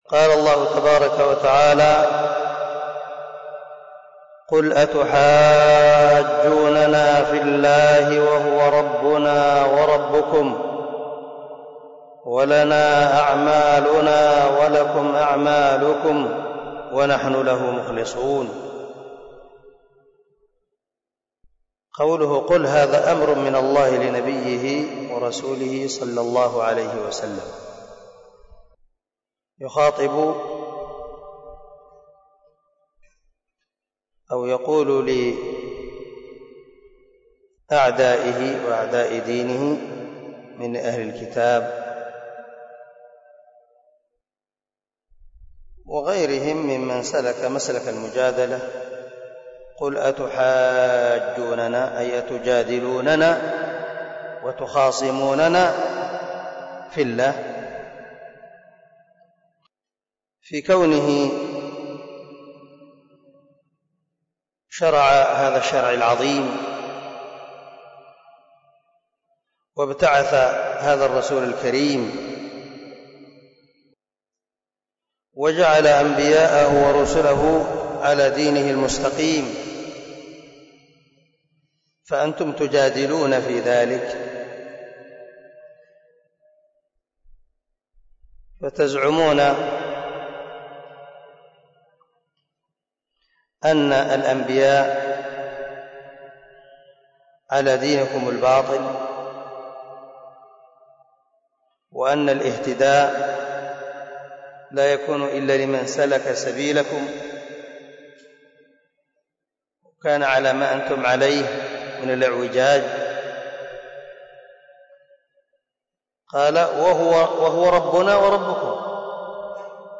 058الدرس 48 تفسير آية ( 139 – 141 ) من سورة البقرة من تفسير القران الكريم مع قراءة لتفسير السعدي